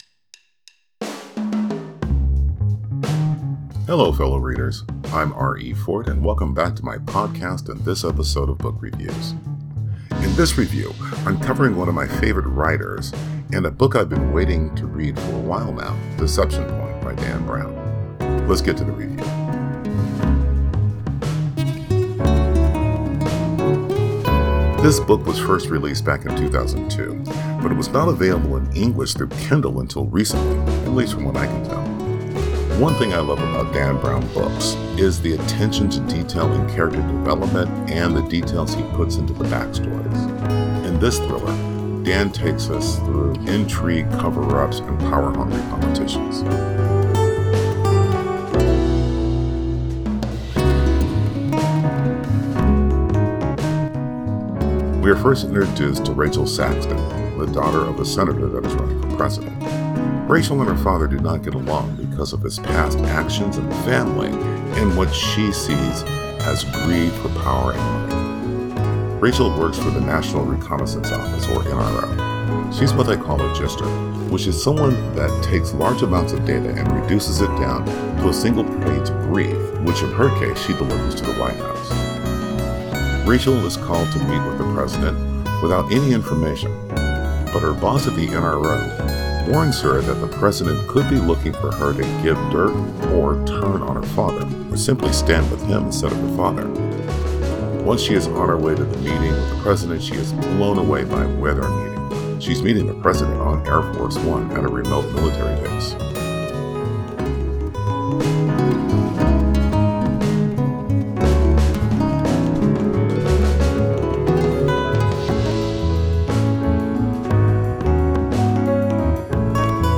Book Review: Deception Point